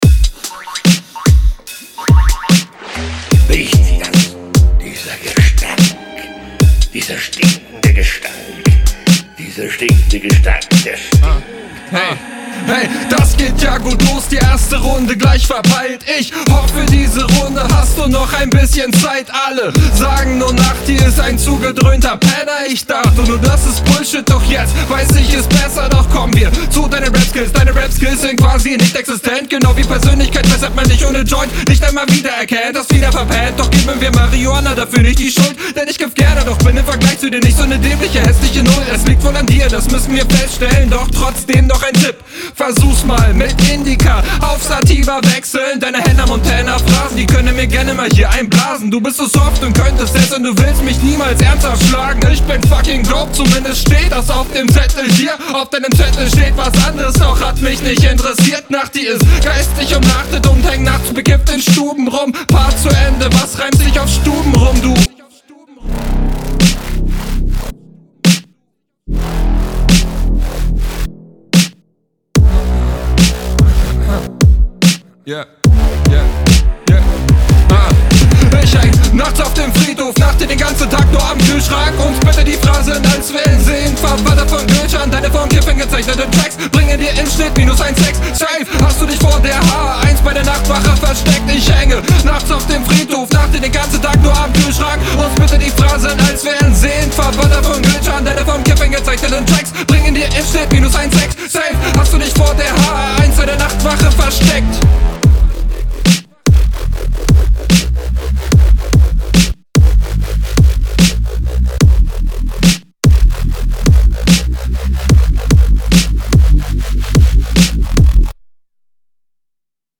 Flow: Nicer Flow, On Point, einige Variationen, Routiniert.